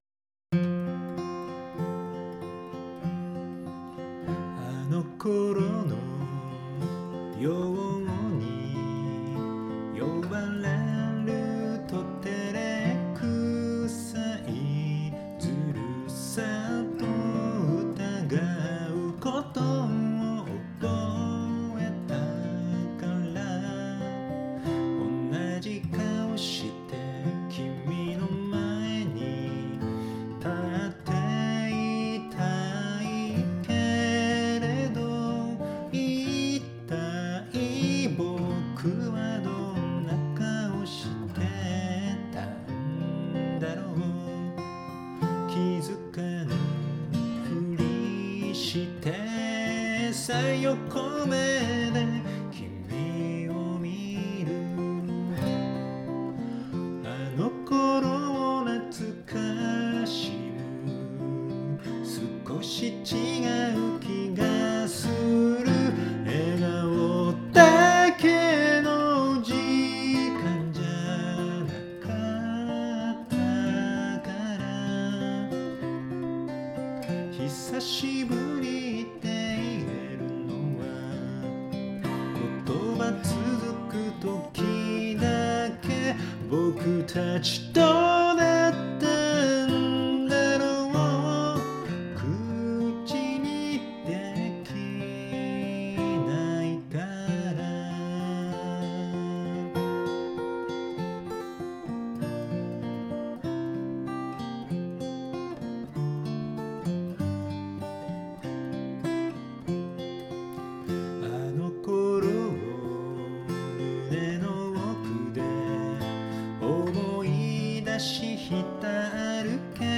ギターの手癖フレーズを楽曲にいれたくて作り出した曲。以前付き合って人と久しぶりに会った出来事をテーマにして作り出した。